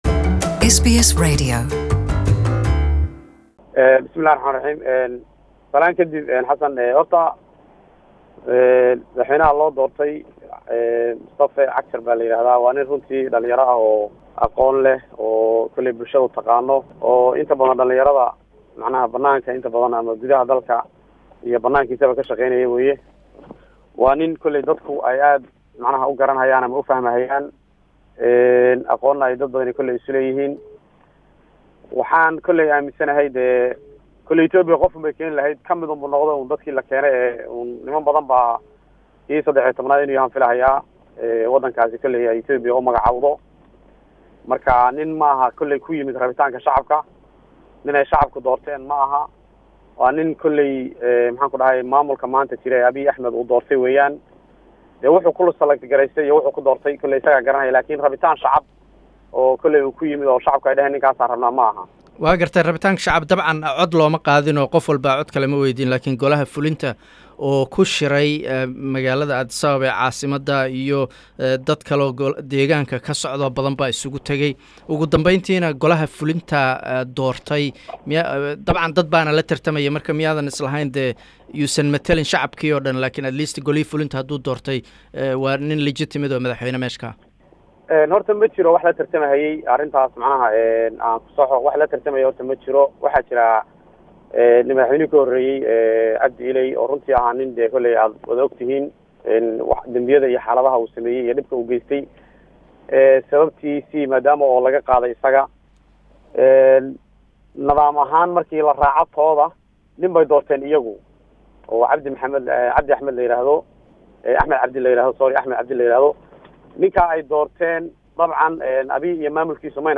Waraysi: Xubin ka tirsan ururka ONLF